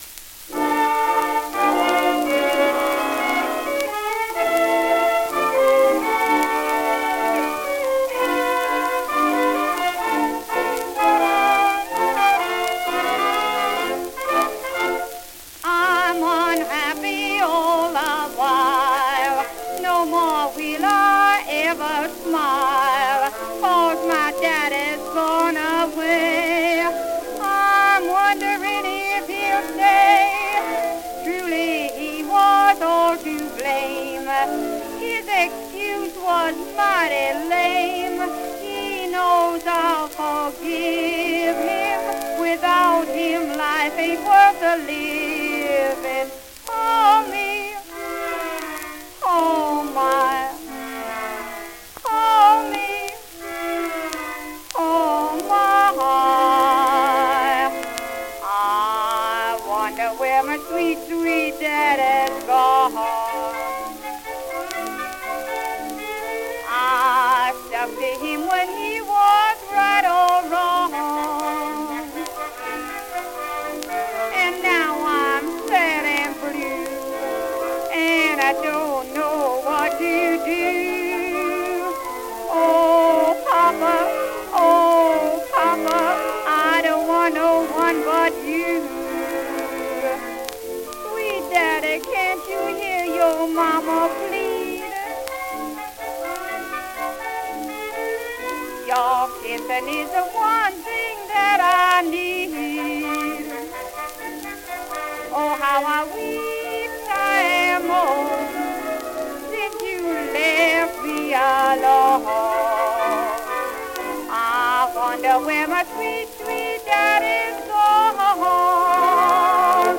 Female vocal solo, with orchestra